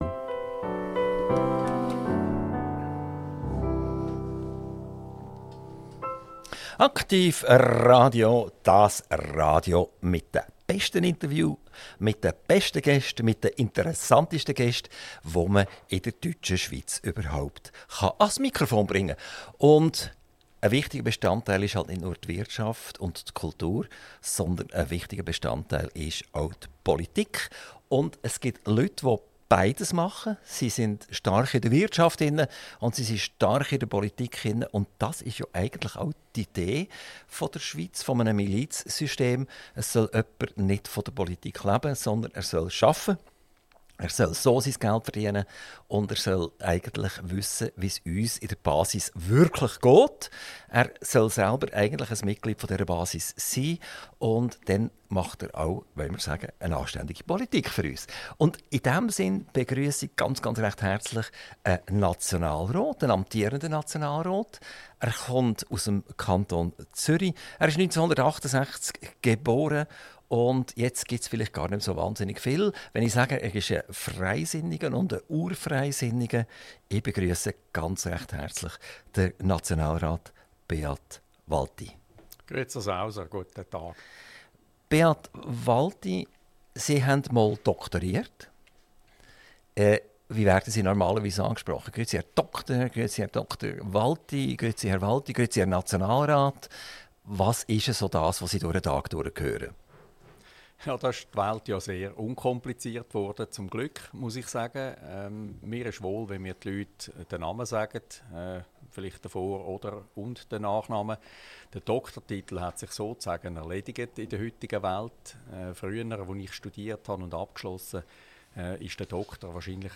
INTERVIEW - Dr. Beat Walti - 25.11.2024 ~ AKTIV RADIO Podcast